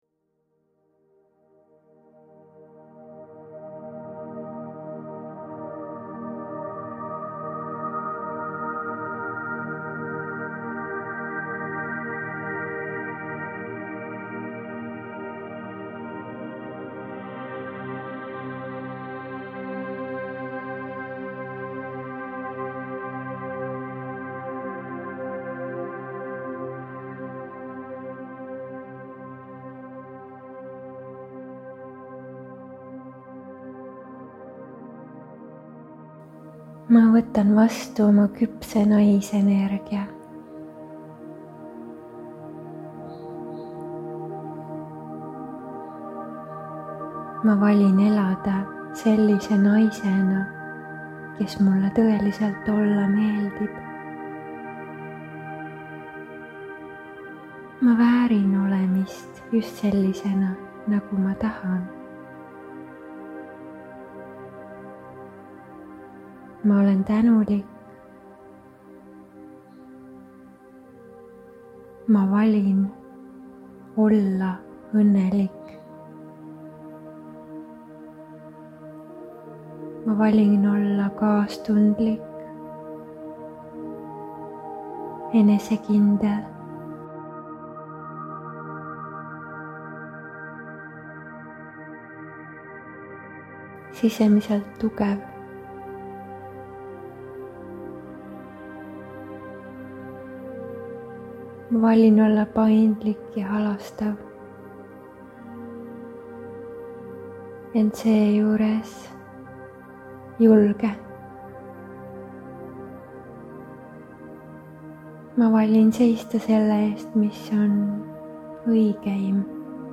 SIIT LEHELT SAAD KUULATA MEDITATSIOONI  KÜPSE JUMALIKU NAISENERGIA VASTUVÕTMINE ENDAS  Seanss naistele, kes valivad tõusta enda allasurumise mustri asemel enda väesse.  See meditatsioon on salvestatud 2023 a Sri Lankal maagilise maailma juhatusel.